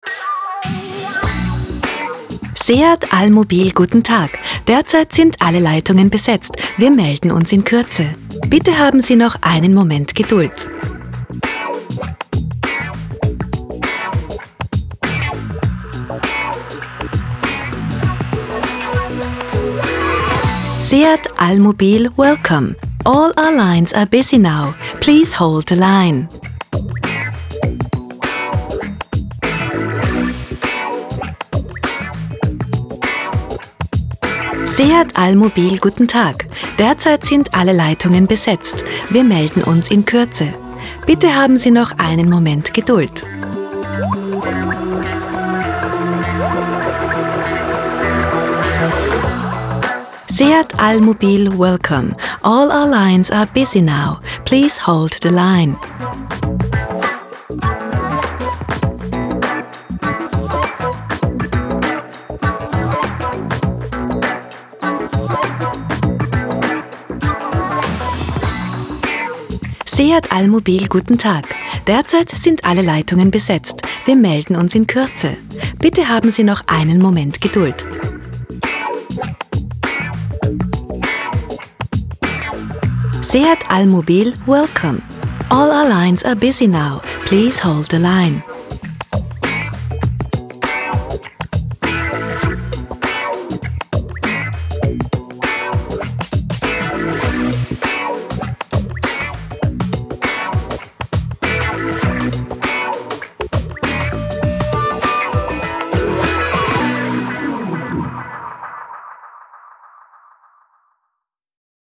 sprecherin
Bei Sprachaufnahmen in Studioqualität geht es – wie bei geschriebenen Texten – darum, den passenden Rhythmus zu finden, den richtigen Ton zu treffen und die gewünschte Stimmung zu transportieren.
Telefon-Anlage: